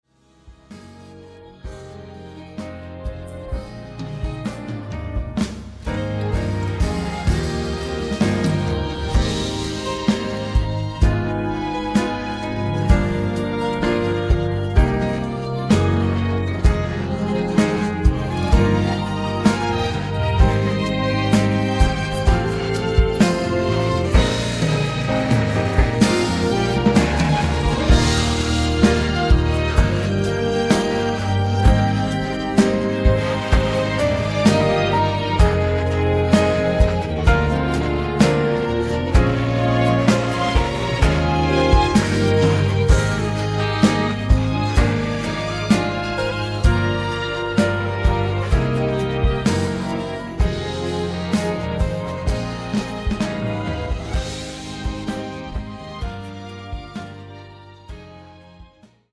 음정 원키 4:30
장르 가요 구분 Voice Cut